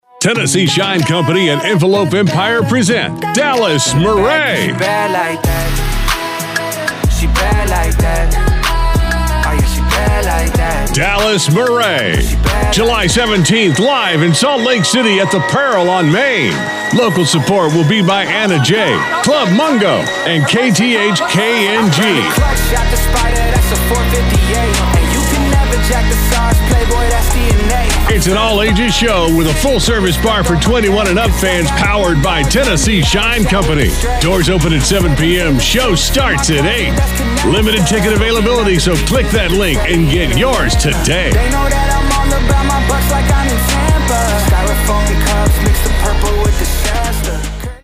Male
Radio veteran with authoritative, high-energy, hard sell delivery for promo, dealership, sports, and concert ads.
Music Promos
Online Concert Spot